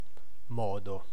Ääntäminen
US : IPA : [ˈmæ.nɜː]